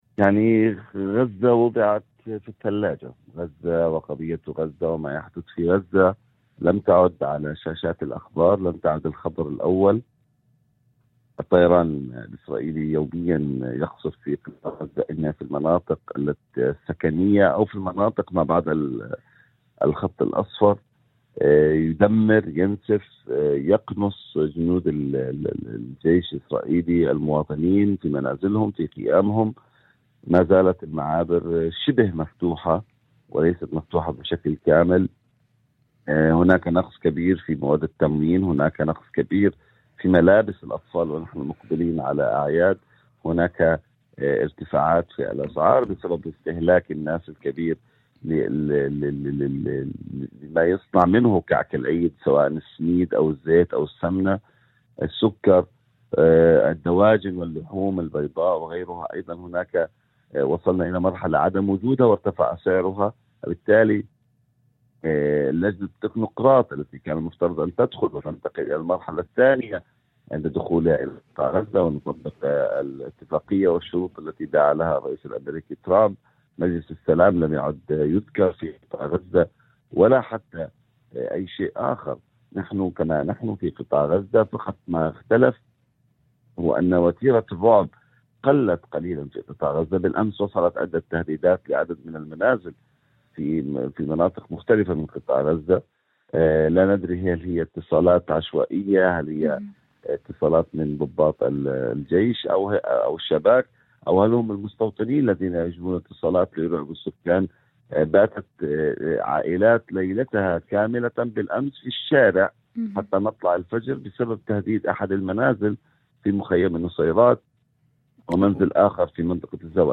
وأضاف في مداخلة هاتفية ضمن برنامج " يوم جديد "، على إذاعة الشمس، أن وتيرة الأحداث في غزة تراجعت في التغطية الإعلامية الدولية مع تصاعد التوتر بين الولايات المتحدة وإيران، رغم استمرار العمليات العسكرية داخل القطاع.